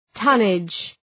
{‘tʌnıdʒ}
tonnage.mp3